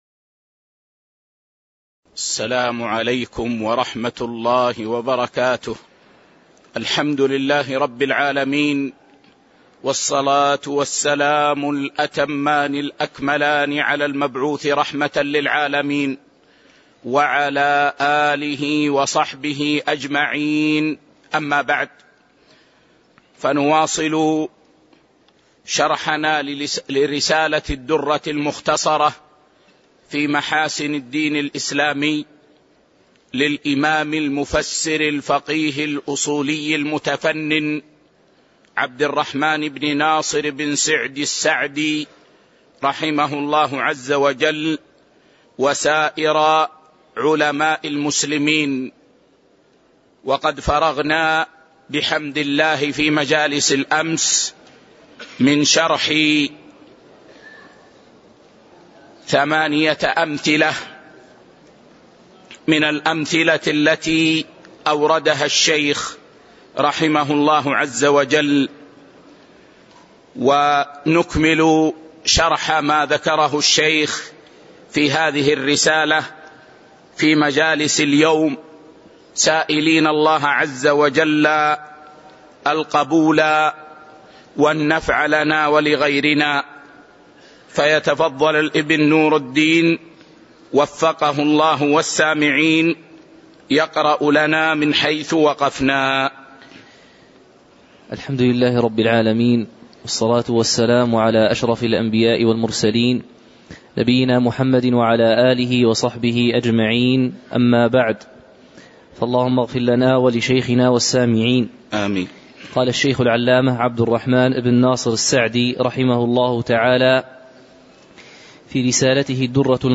تاريخ النشر ١٨ شعبان ١٤٤٤ المكان: المسجد النبوي الشيخ